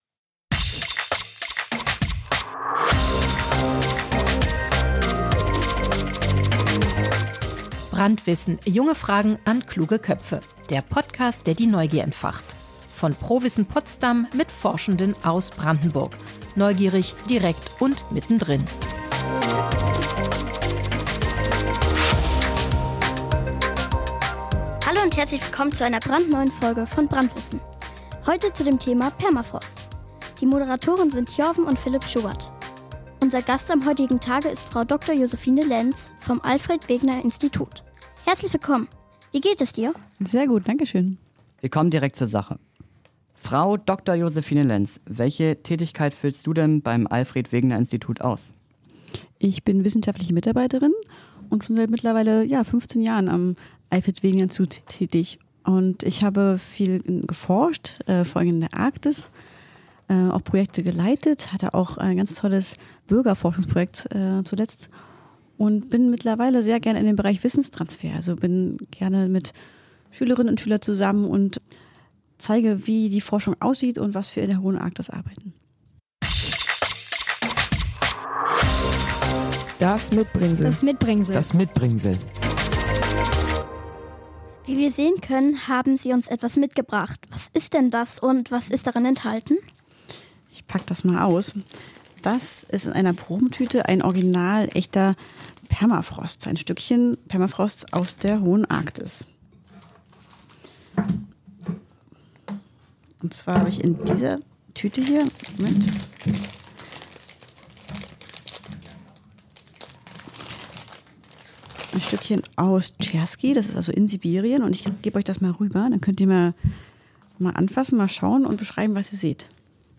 Im Studio dabei: Ein Stück echter Permafrost.